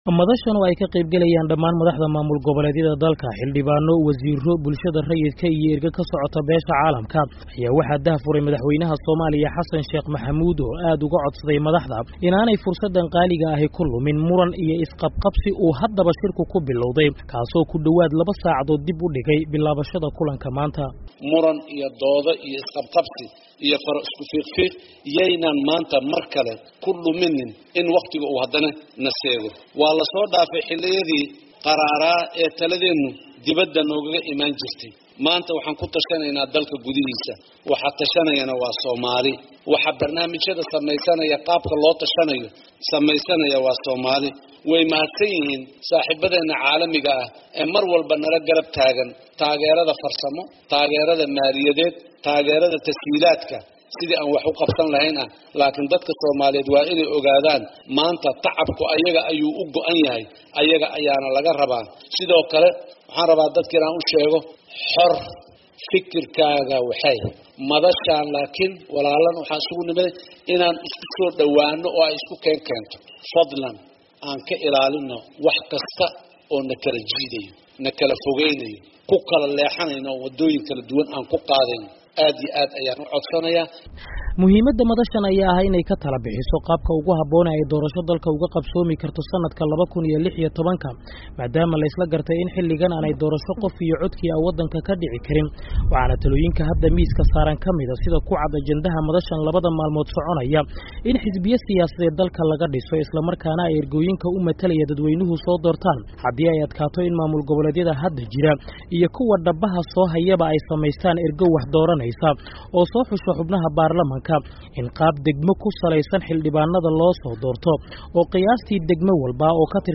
Warbixin: Furitaanka Shirka Madasha